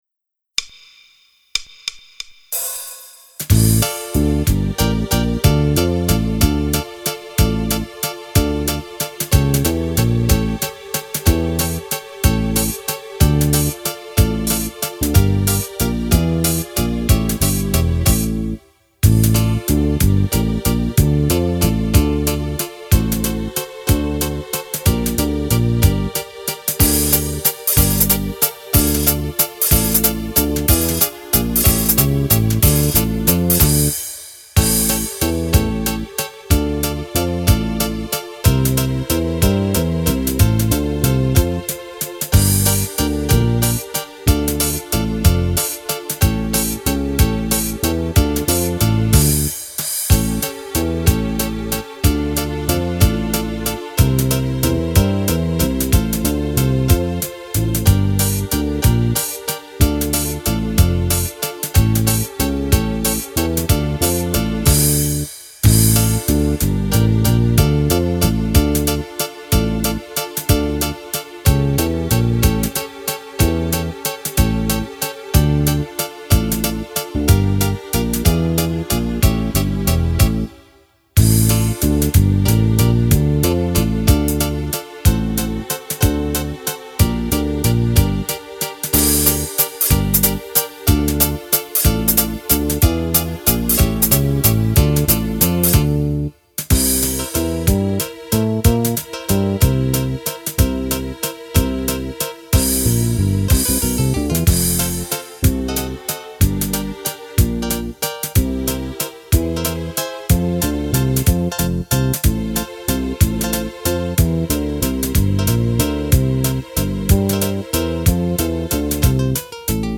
Valzer musette
Fisarmonica